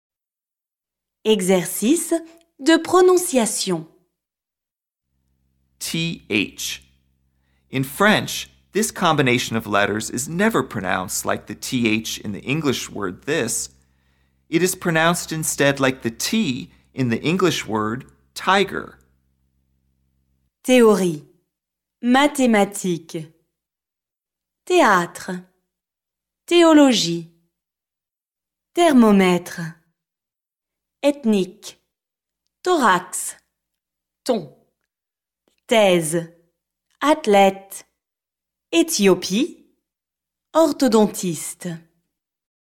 PRONONCIATION
th – In French, this combination of letters is never pronounced like the “th” in the English word “this.” It is pronounced instead like the “t” in the English word “tiger.”